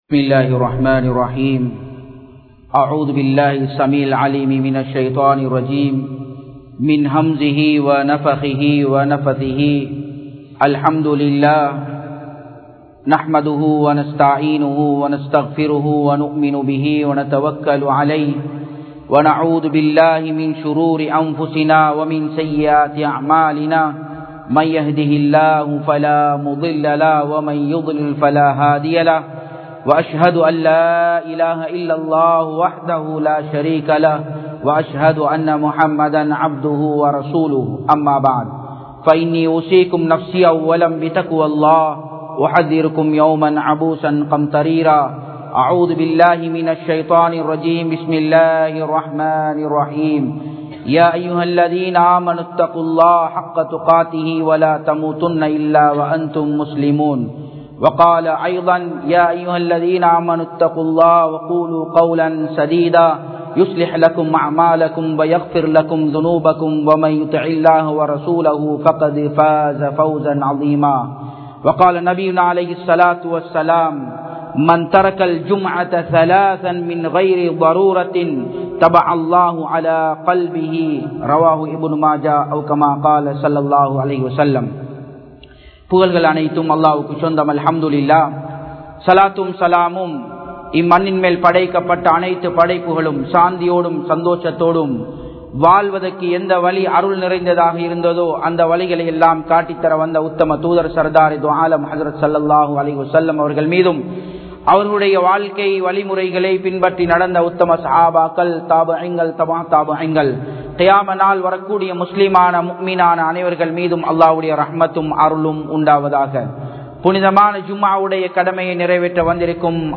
Jumma Thinaththai Marappavarhale (ஜூம்ஆ தினத்தை மறப்பவர்களே) | Audio Bayans | All Ceylon Muslim Youth Community | Addalaichenai
Japan, Nagoya Port Jumua Masjidh 2017-07-28 Tamil Download